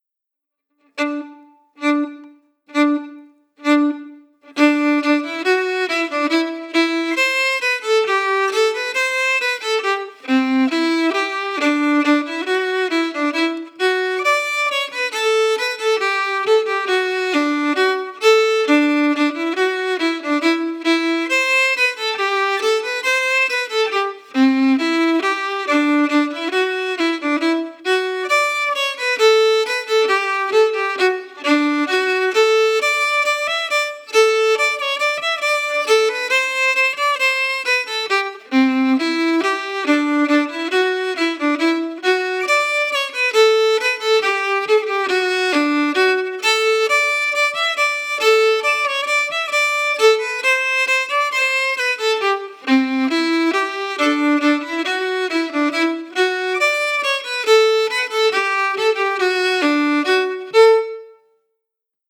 Key: D-major
Form: Reel, Song
Melody – Slow
R:Scottish Reel Song
Linkumdoddie-slow.mp3